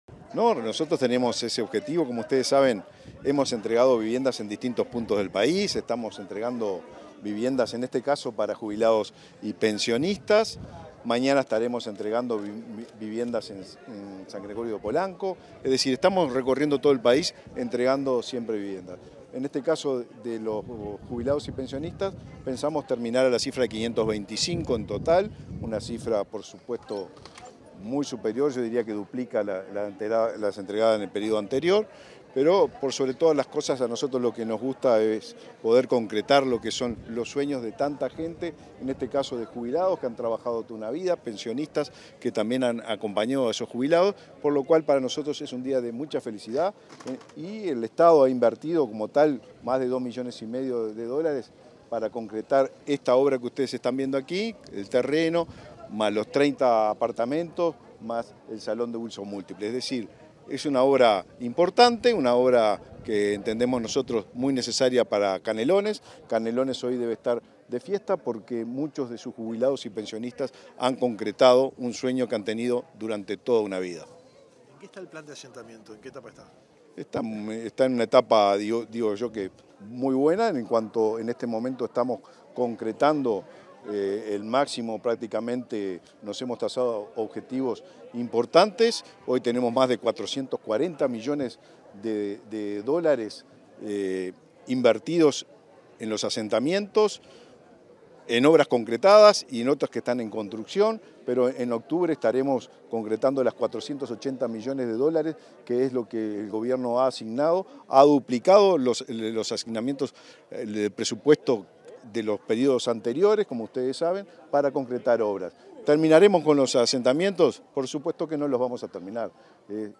Declaraciones del ministro de Vivienda, Raúl Lozano
Declaraciones del ministro de Vivienda, Raúl Lozano 25/07/2024 Compartir Facebook X Copiar enlace WhatsApp LinkedIn Luego de la entrega de viviendas a jubilados y pensionistas aportantes al Banco de Previsión Social en el departamento de Canelones, este 25 de julio, el ministro de Vivienda y Ordenamiento Territorial, Raúl Lozano, dialogó con la prensa.